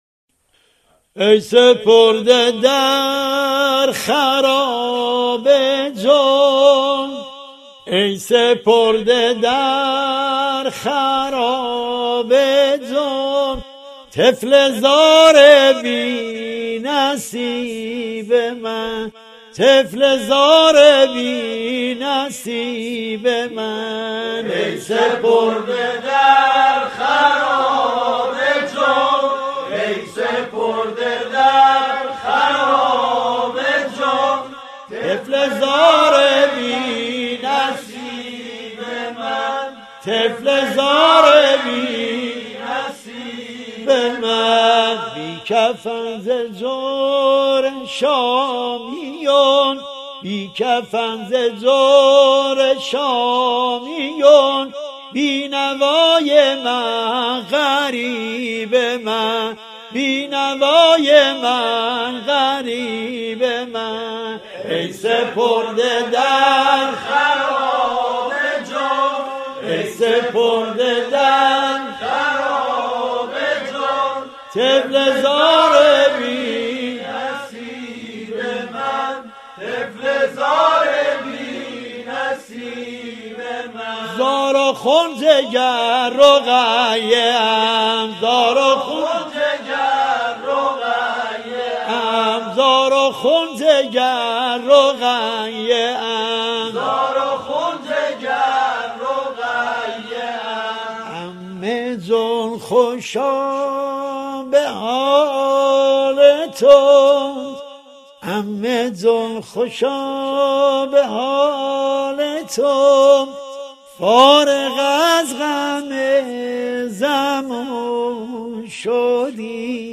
نوحه سنتی حضرت رقیه+صوتی
تهران- الکوثر: دستگاه سه‌گاه: این نوحه که دارای مصرع‌های بلند و کوتاه و ضرب آهنگ کند و تند است، در گوشه‌ زابل خوانده شده است: